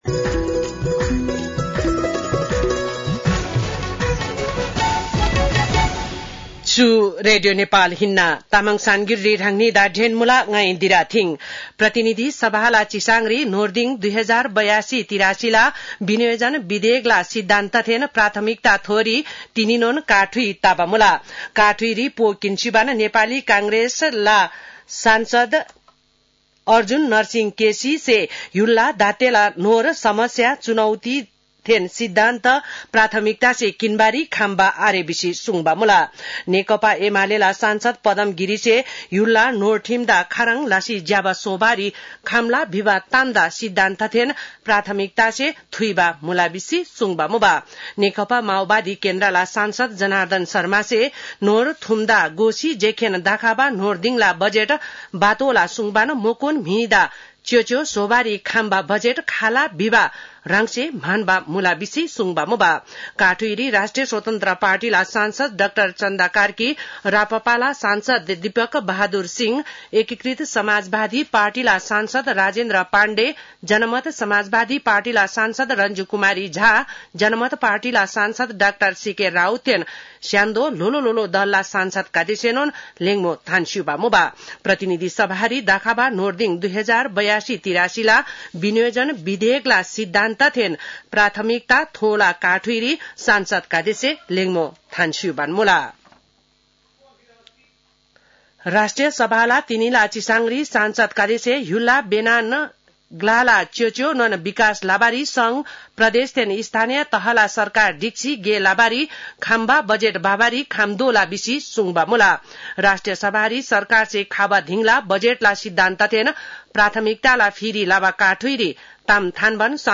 तामाङ भाषाको समाचार : ३१ वैशाख , २०८२